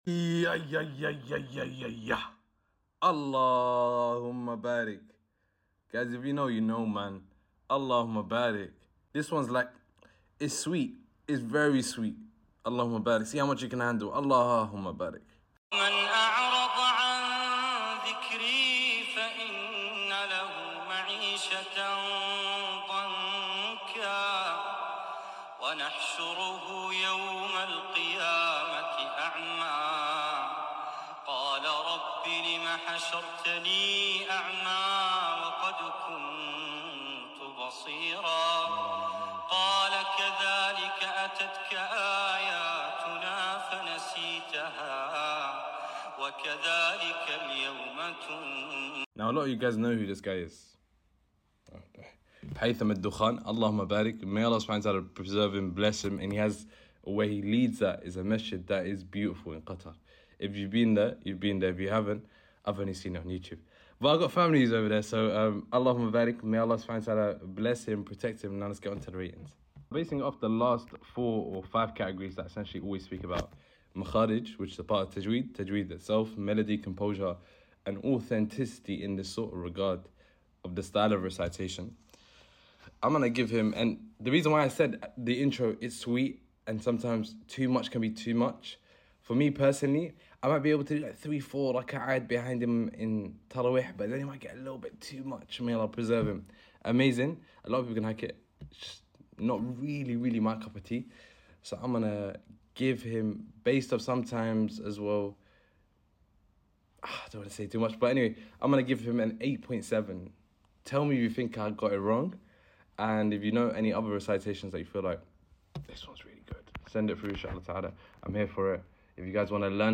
This Quran recitation genuinely amazing to hear and has a powerful meaning behind it. Surah taha. It comes with a load of tajweed.